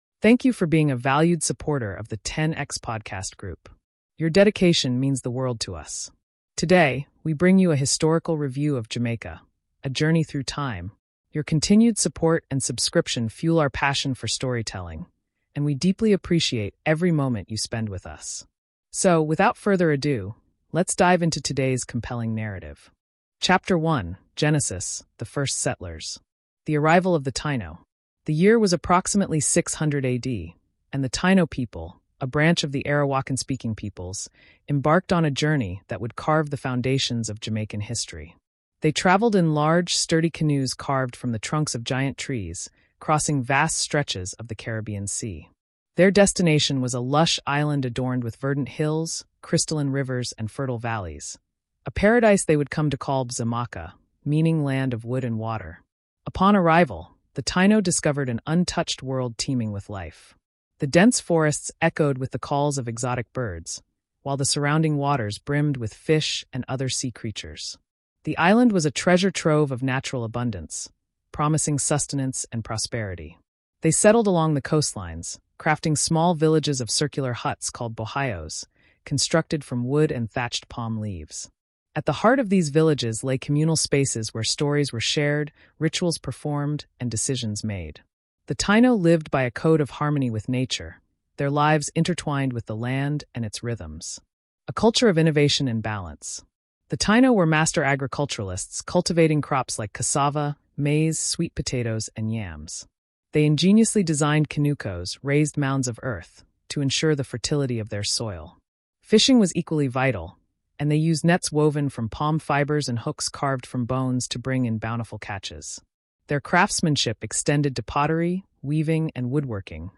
Through vivid storytelling, expert interviews, stunning visuals, and authentic music, this series celebrates the spirit of a nation that has left an i